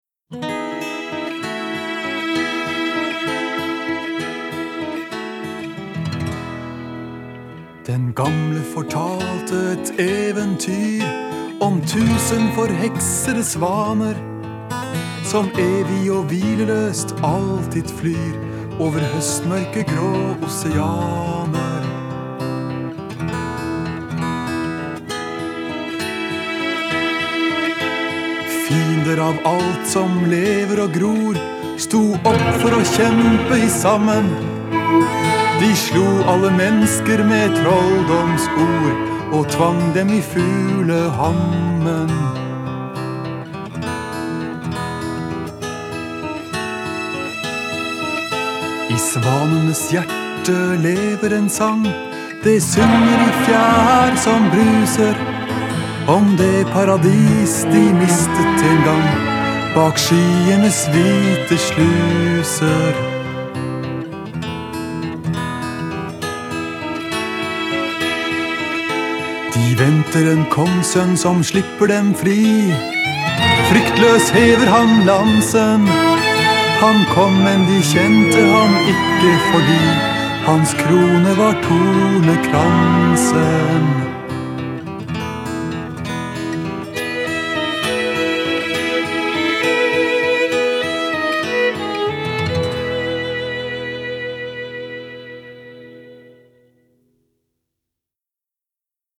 Folk Music
христианской песенной группой